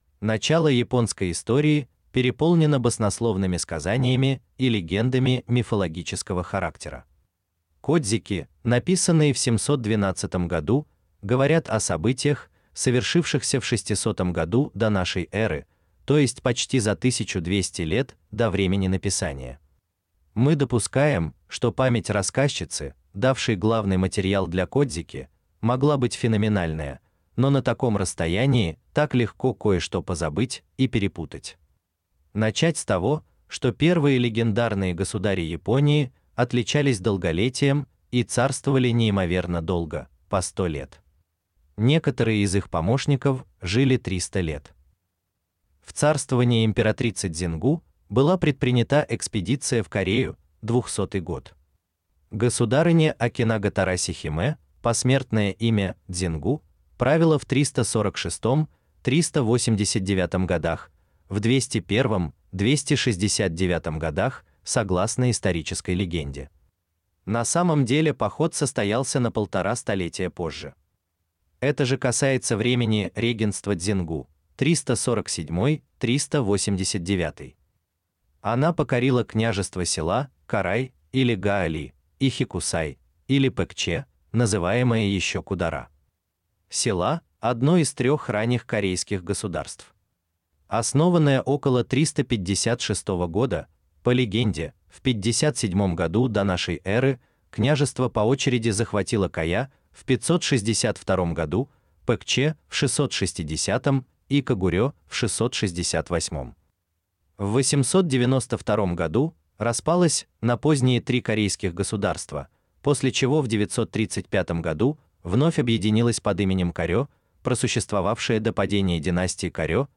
Аудиокнига В стране восходящего солнца. Записки русского консула о Японии | Библиотека аудиокниг